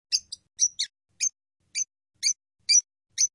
Agapornis (Inseparables) (Agapornis spp.)